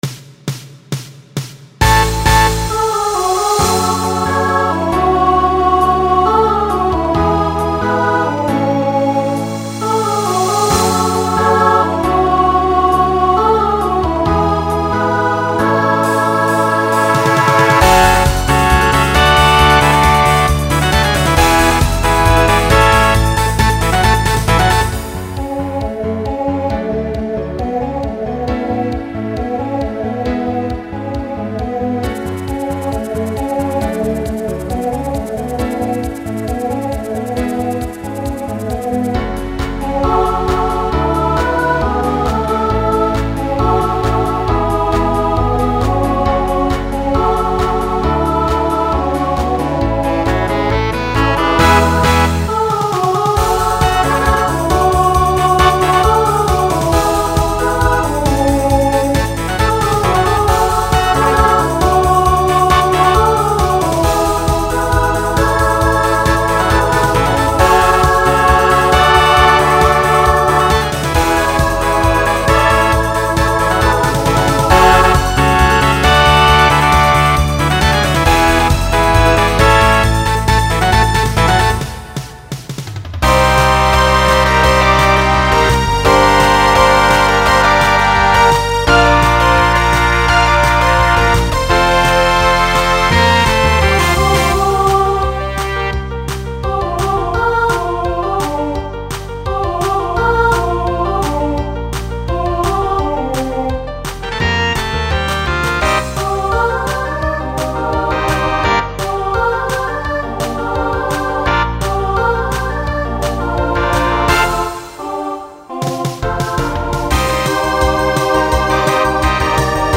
Pop/Dance , Rock
Opener Voicing SSA